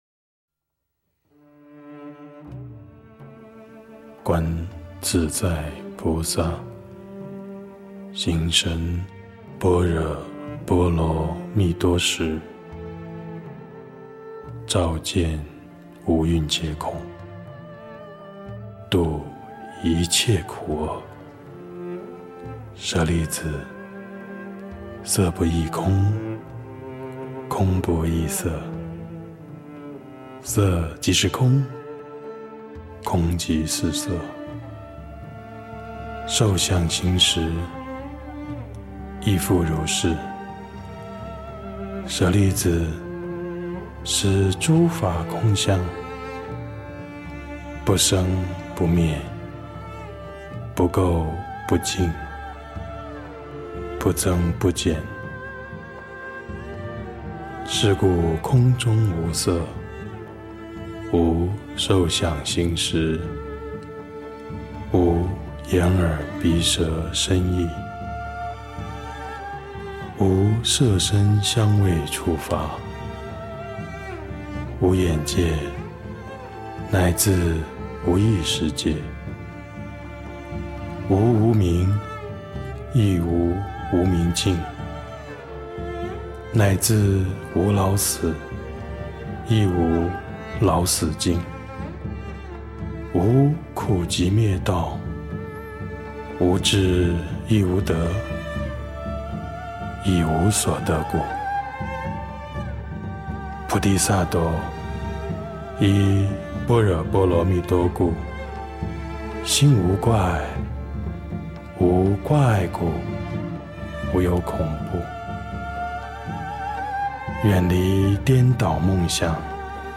诵经
佛音 诵经 佛教音乐 返回列表 上一篇： 心经 下一篇： 大悲咒 相关文章 清净法身佛--风潮般若海系列 清净法身佛--风潮般若海系列...